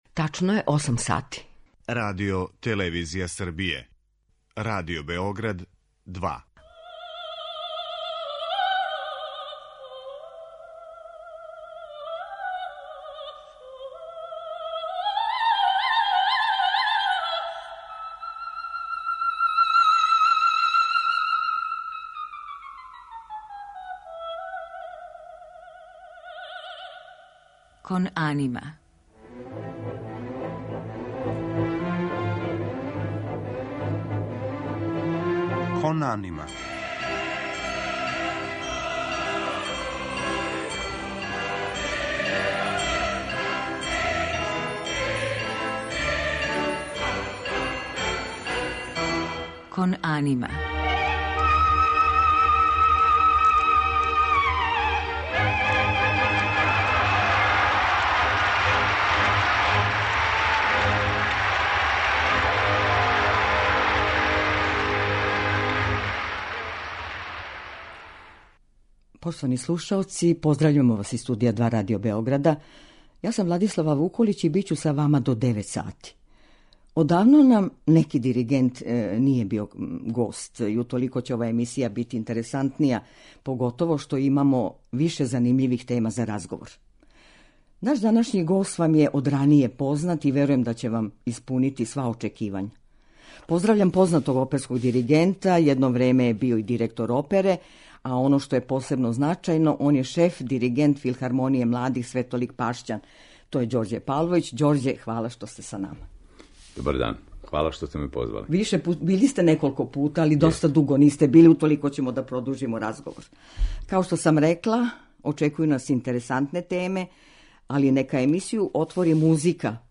У музичком делу емисије биће емитовани фрагменти из споменутих опера у извођењу наших еминентних оперских уметника.